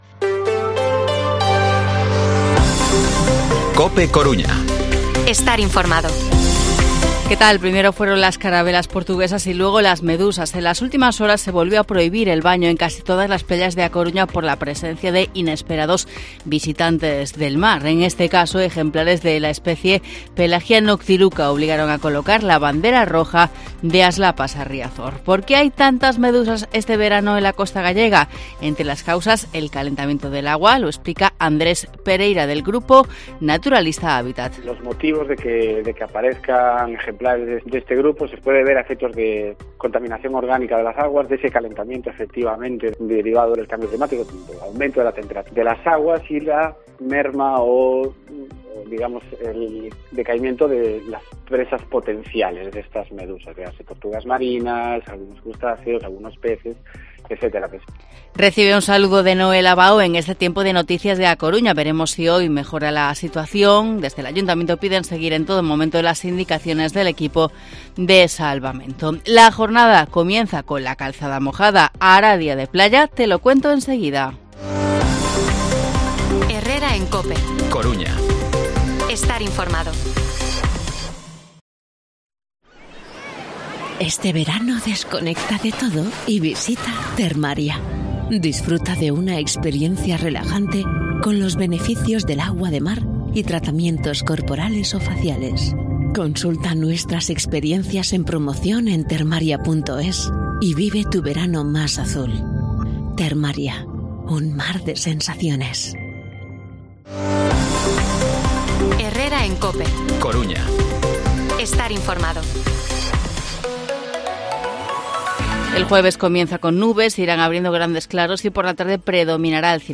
Informativo Herrera en COPE Coruña jueves, 31 de agosto de 2023 8:24-8:29